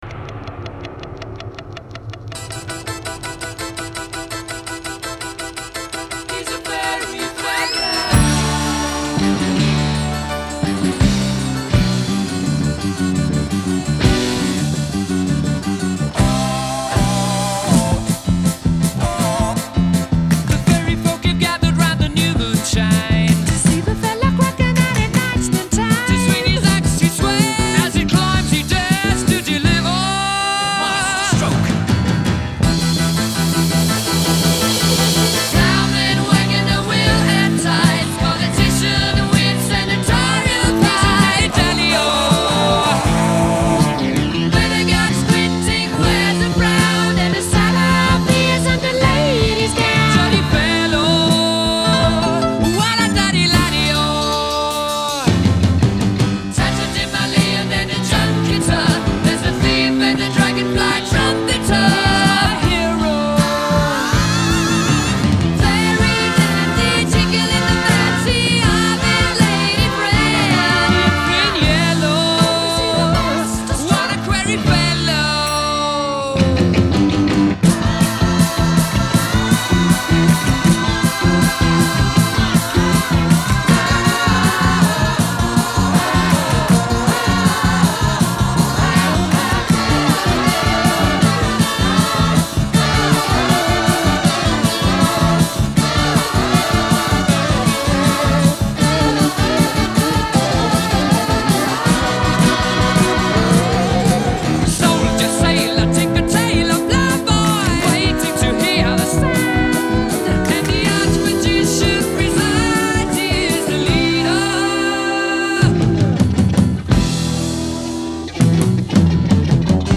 прогрессив-рока